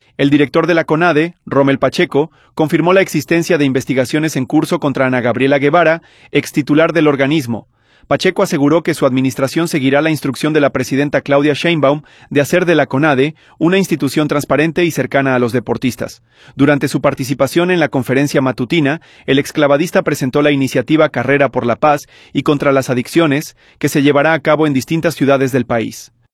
Pacheco aseguró que su administración seguirá la instrucción de la presidenta Claudia Sheinbaum de hacer de la Conade una institución transparente y cercana a los deportistas. Durante su participación en la conferencia matutina, el exclavadista presentó la iniciativa Carrera por la Paz y contra las Adicciones, que se llevará a cabo en distintas ciudades del país.